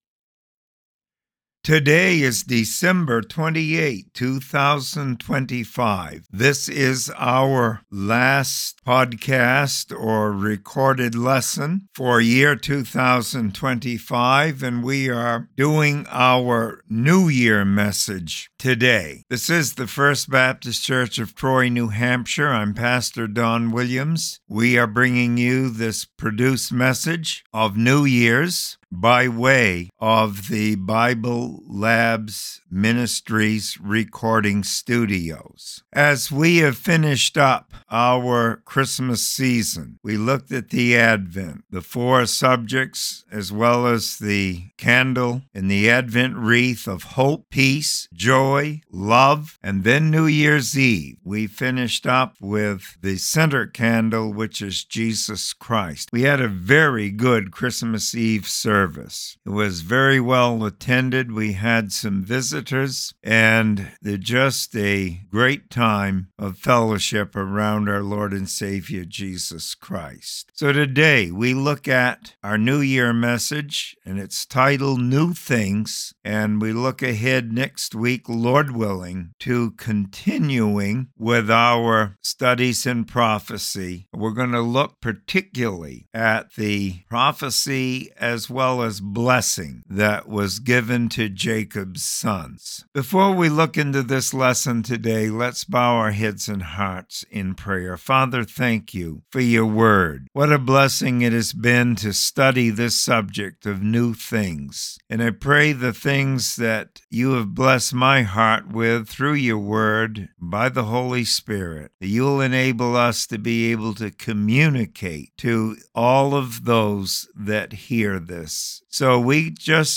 Special Sermons Good Friday - Who is the Loser?